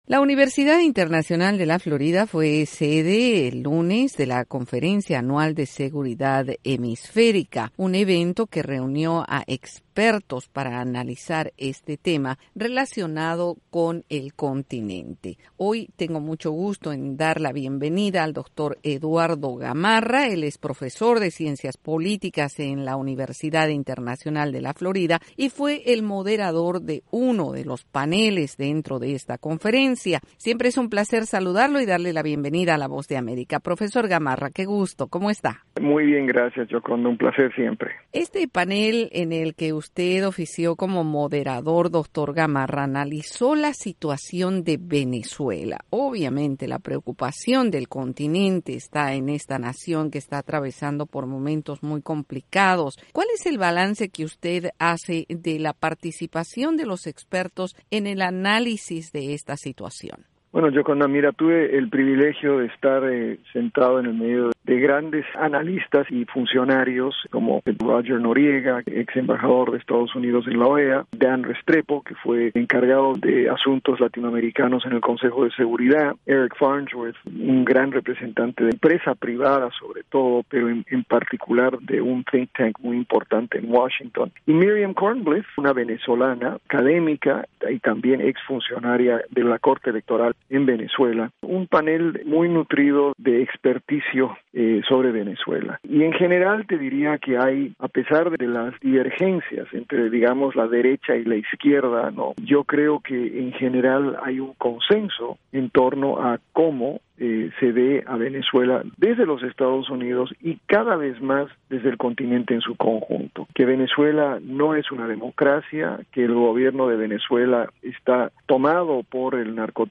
y en entrevista con la Voz de América compartió algunos conceptos.